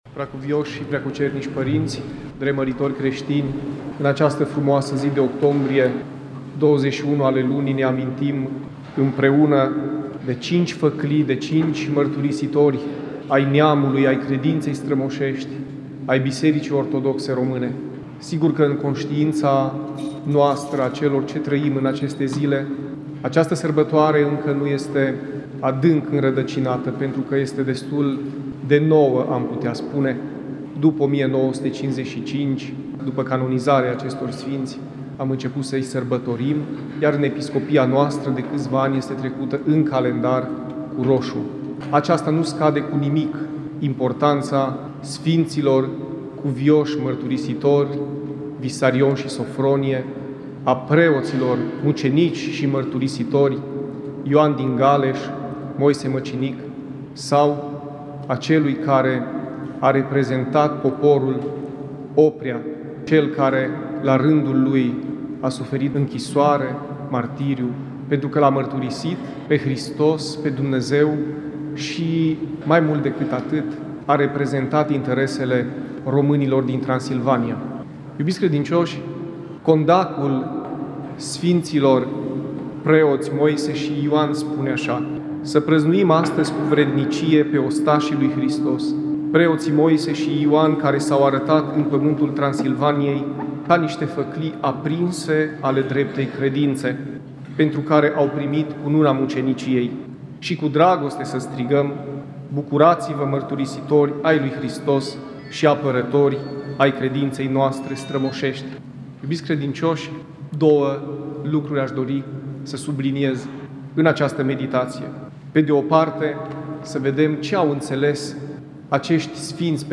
Cuvinte de învățătură
Predică la Sfinții Mărturisitori Ardeleni Play Episode Pause Episode Mute/Unmute Episode Rewind 10 Seconds 1x Fast Forward 30 seconds 00:00